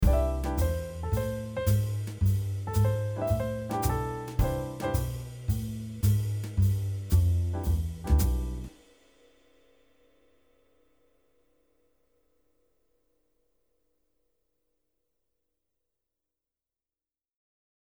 8. Using articulations (i.e. slides, staccato notes)
(The first note would be a slide on guitar, but expresesed as a grace note on piano).
Use-articulations-i.e.-slides-staccato-notes.mp3